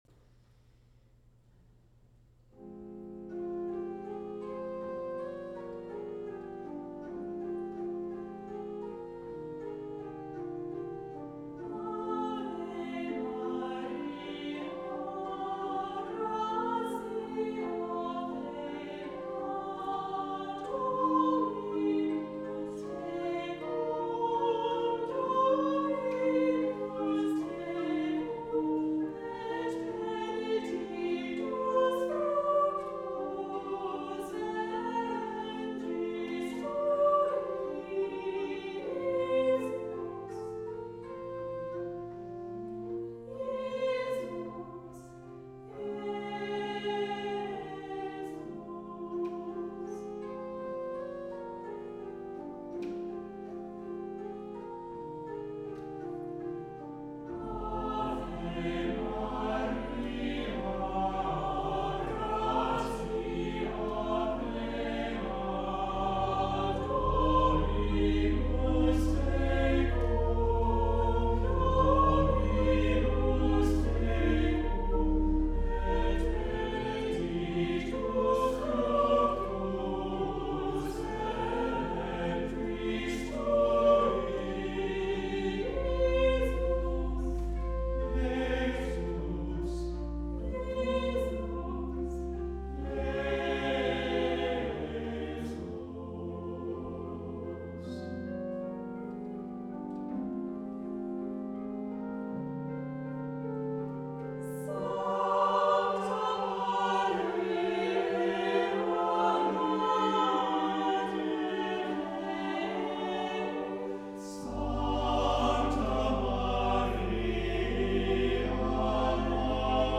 • Music Type: Choral
• Voicing: SATB
• Accompaniment: Organ